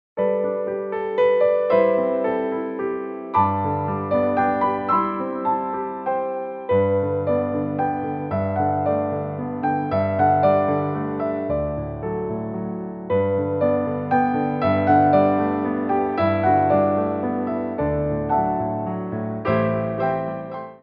Pirouettes
3/4 (16x8)